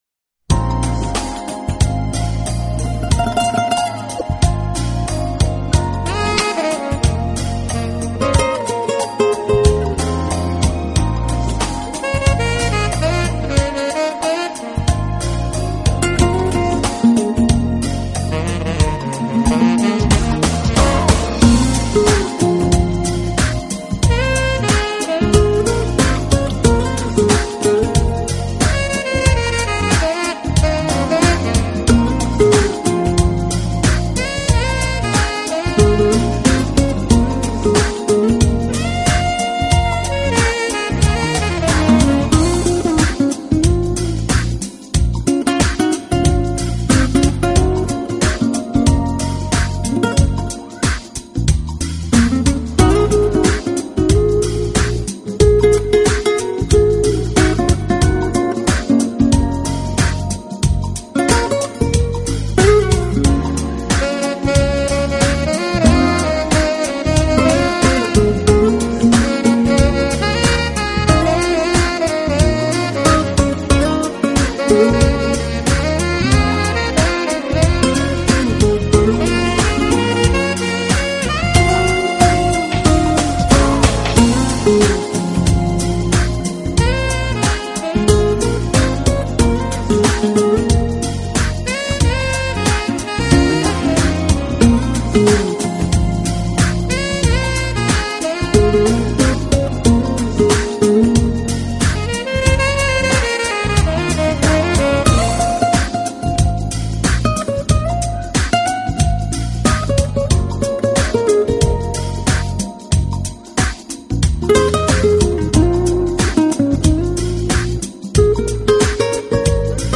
【爵士吉他】
一套精彩的爵士吉他合辑，荟萃了当今众多艺术名家，集爵士吉他之精华。